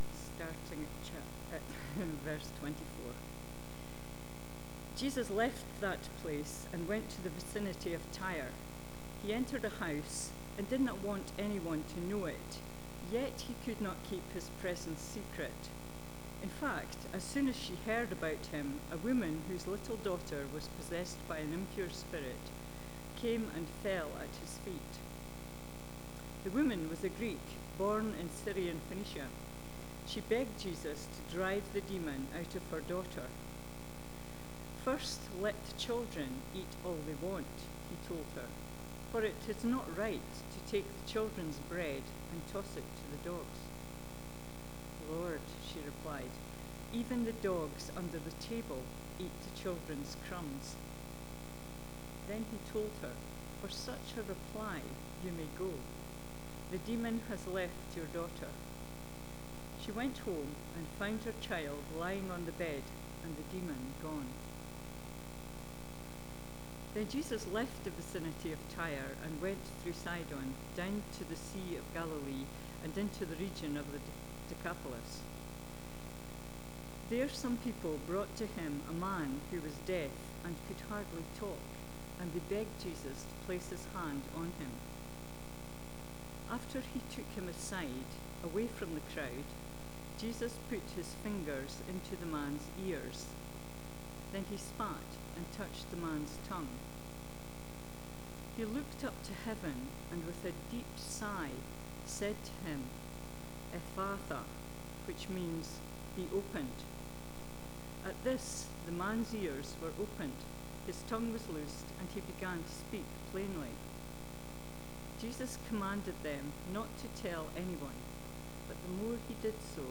2025 Jesus never turns anyone away Preacher